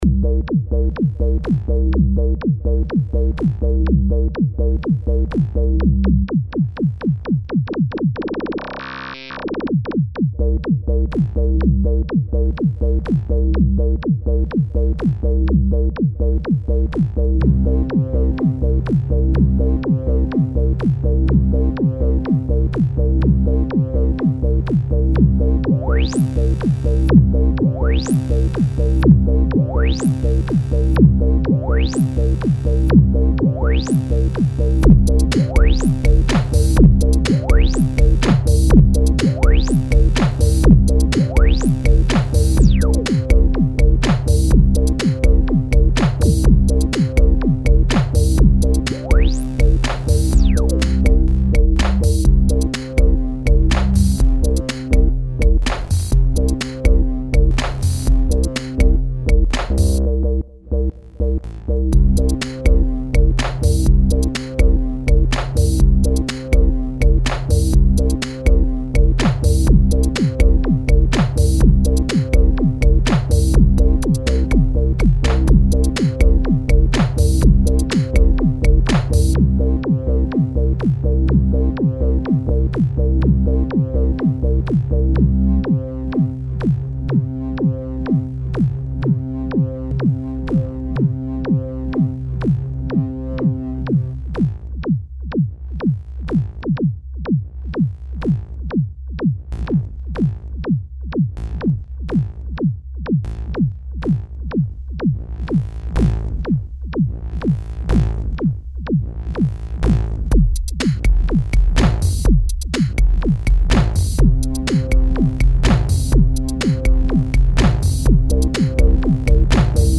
demo Machinery - all poly800 : overdub+drum + vocoder Roland SVC350
All poly800 + Volca beats drums